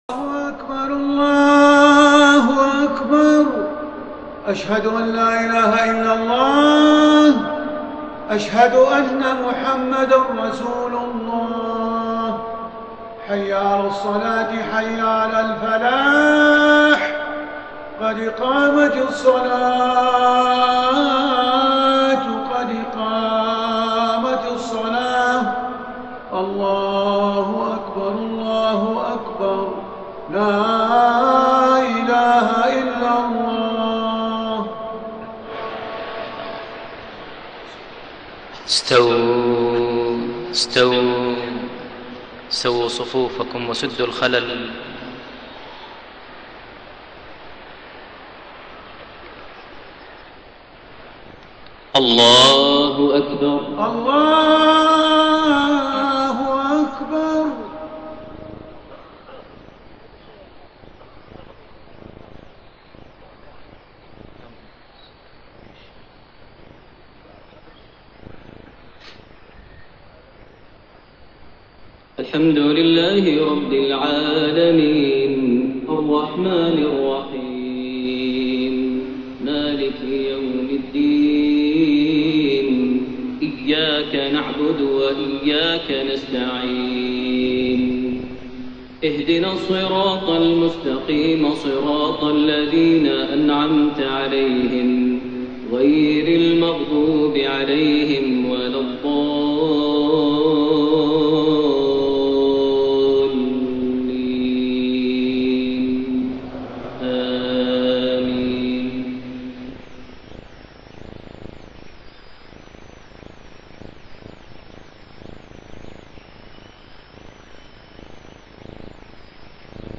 صلاة المغرب 17 رجب 1432هـ | سورتي الضحى و الهمزة > 1432 هـ > الفروض - تلاوات ماهر المعيقلي